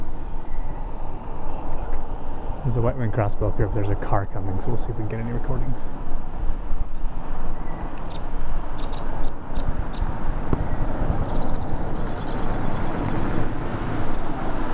White-winged Crossbill
Summerhill (Salt Rd. near Dresser Rd.), 13 August 2008
Male White-winged Crossbill flying away (and a car)